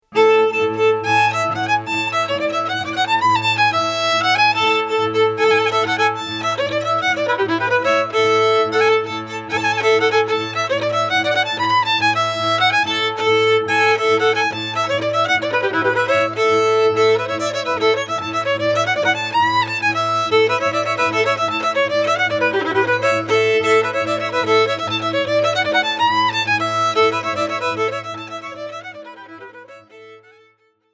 Violin
C Clarinet
Accordions, Tsimbl
Bass Cello
Genres: Klezmer, Polish Folk, Folk.